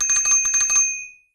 描述：响起了自行车铃声。用Zoom H2在Poblenou UPF校园前录制的。和谐和放松的声音。